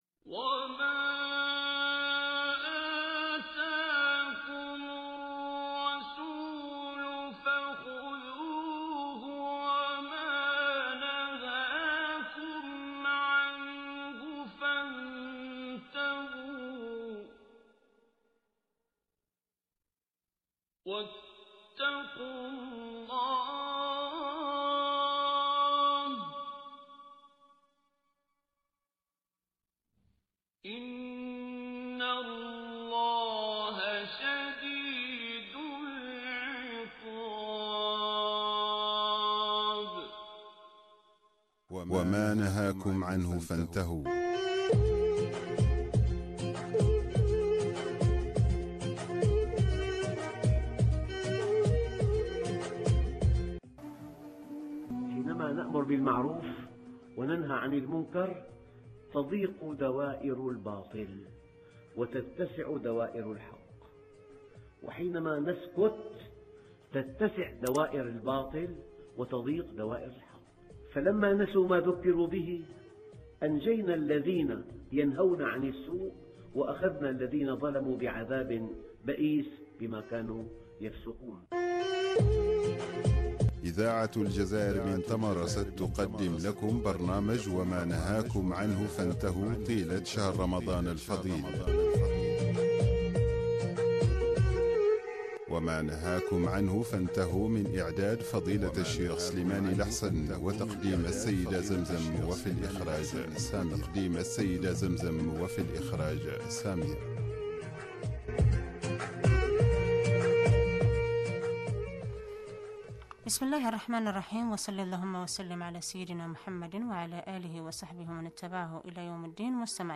برامج إذاعية